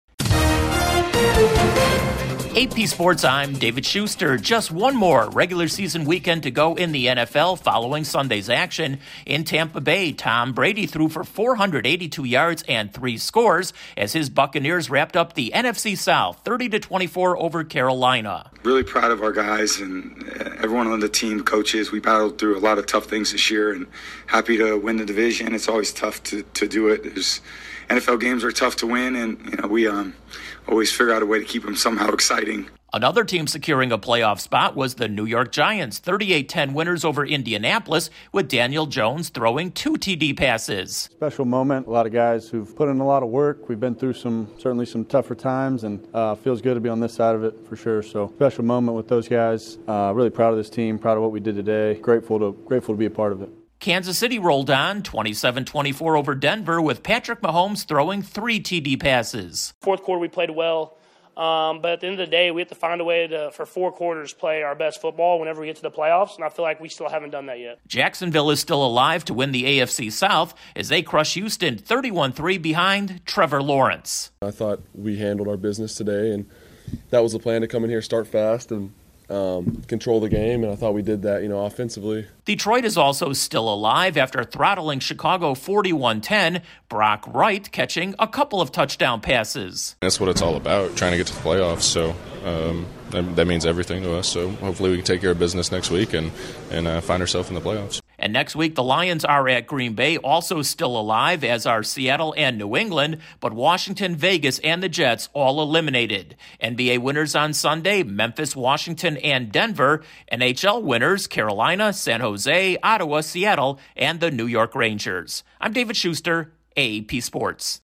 The NFL playoff picture gets clearer with wins by the Buccaneers and Giants. Correspondent